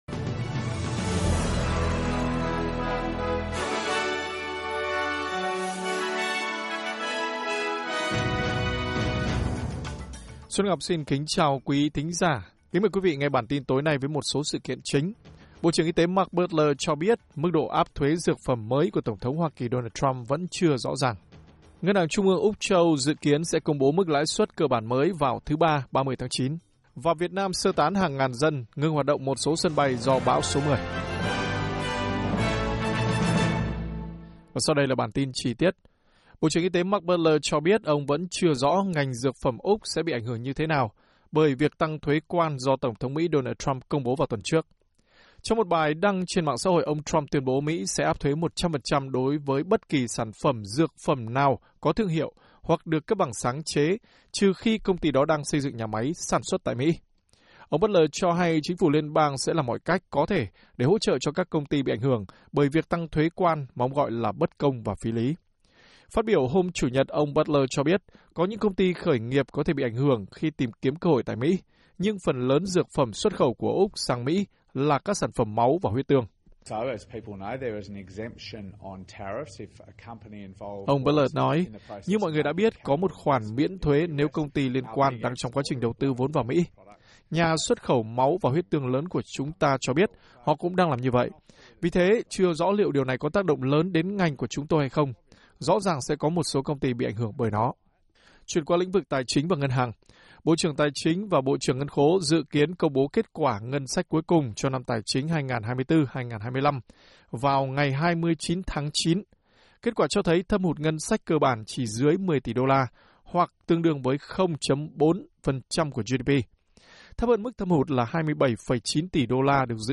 Bản tin của SBS Tiếng Việt sẽ có những nội dung chính.